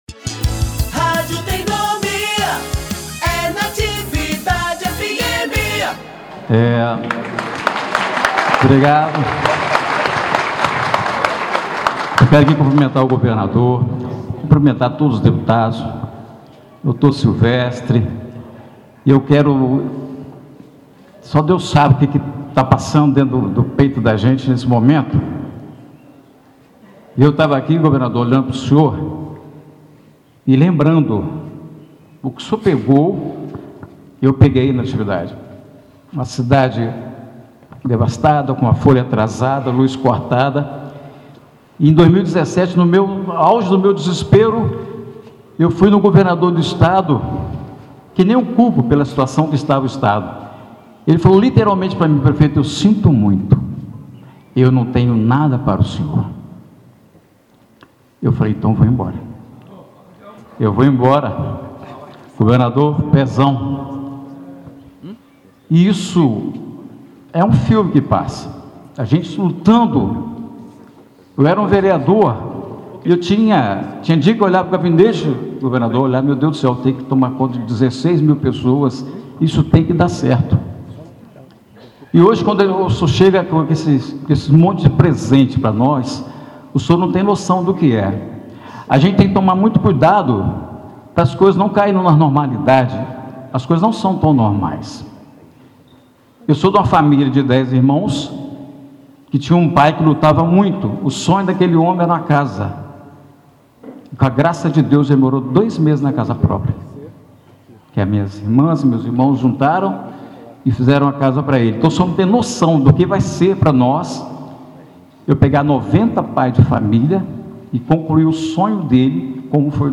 OUÇA o discurso do Governado Cláudio Castro durante visita a Natividade
Em solenidade no final da tarde desta terça-feira (10), o governador Cláudio Castro, realizou simbolicamente a entrega de cheque de quase R$ 4 milhões fruto da venda da CEDAE ao prefeito Severiano Rezende, além de ter assinado a “intenção” de construir futuramente 90 casas populares na cidade.
DISCURSO.mp3